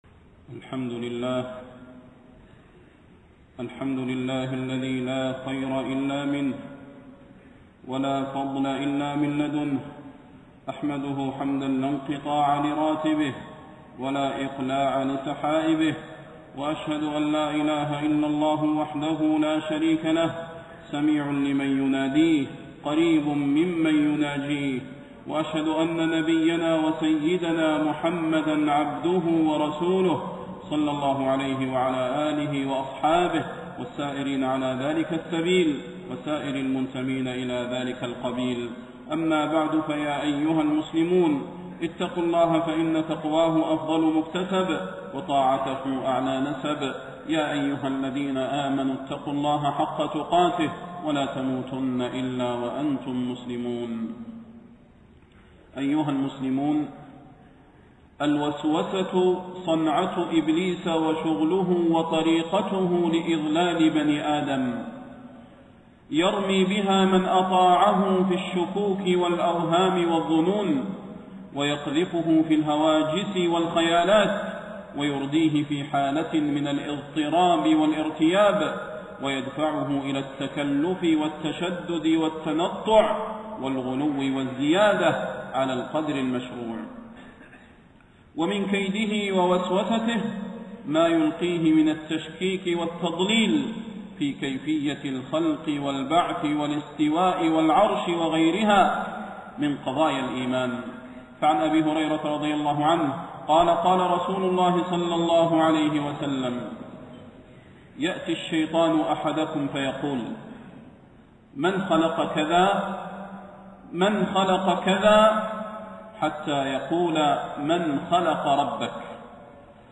تاريخ النشر ٢٧ شوال ١٤٣٣ هـ المكان: المسجد النبوي الشيخ: فضيلة الشيخ د. صلاح بن محمد البدير فضيلة الشيخ د. صلاح بن محمد البدير وسوسة إبليس لبني آدم